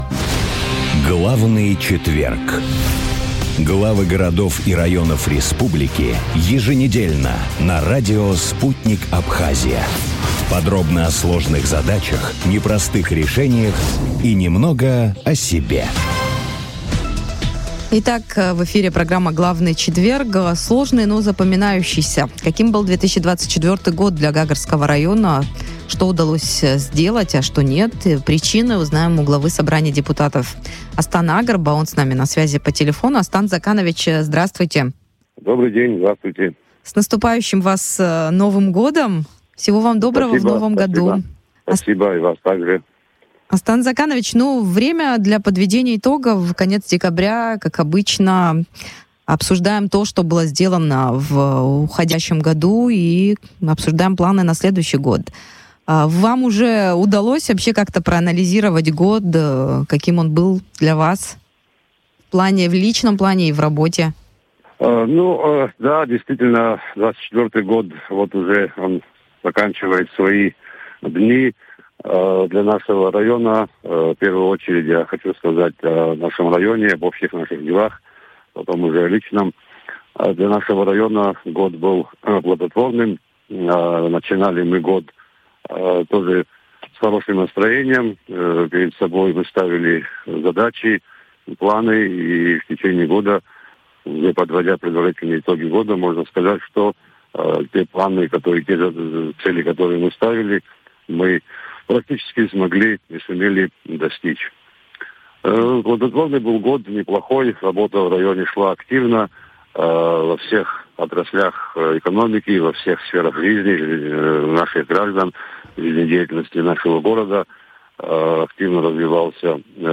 Глава Собрания депутатов Гагрского района Астан Агрба в эфире радио Sputnik рассказал, каким стал 2024 год для района.